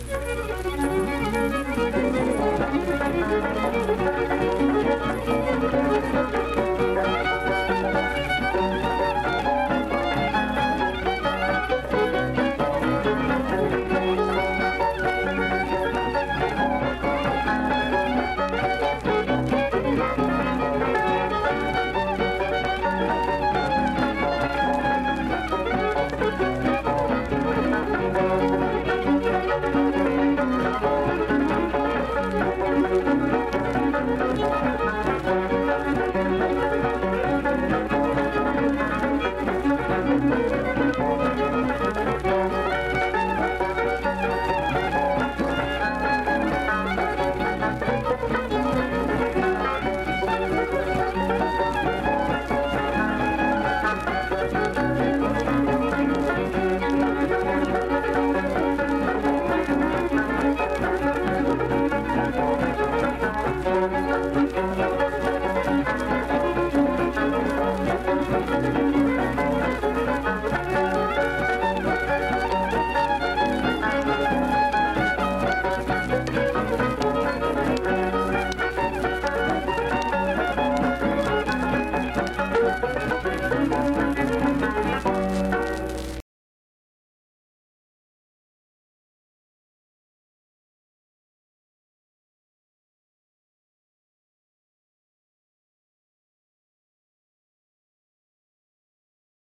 Instrumental performance with fiddle, banjo, and guitar.
Instrumental Music
Banjo, Guitar, Fiddle
Wood County (W. Va.), Vienna (W. Va.)